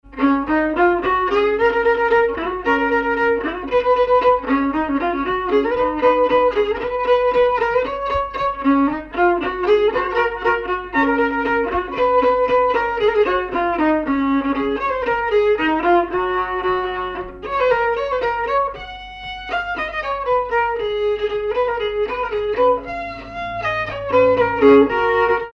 danse : polka
circonstance : bal, dancerie
Pièce musicale inédite